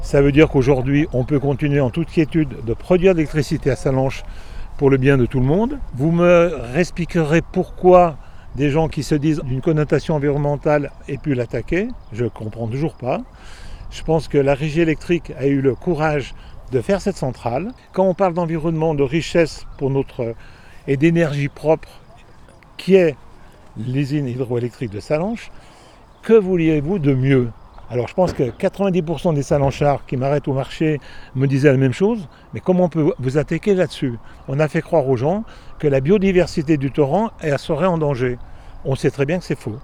Pour le maire, Georges Morand, c’est une bonne nouvelle qui va dans le bon sens.
ITG Georges Morand - centrale hydroélectrique Sallanches jugement de fond (39'') - 8h17h